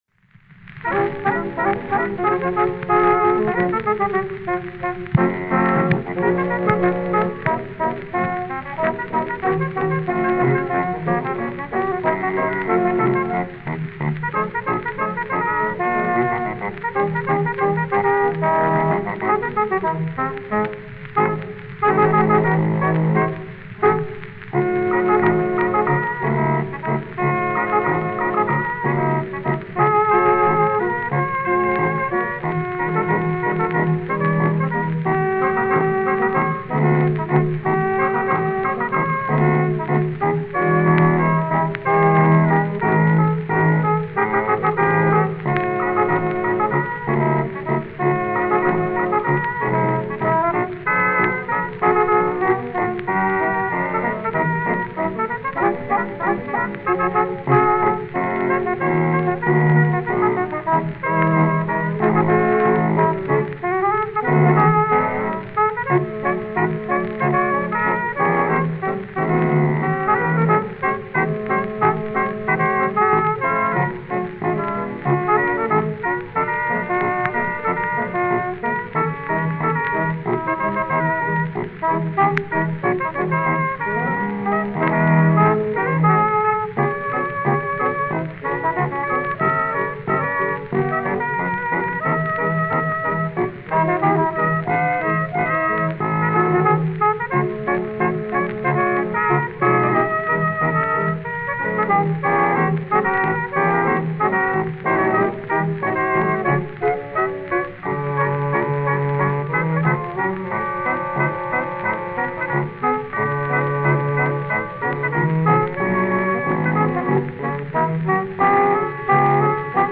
Medley Fox-trot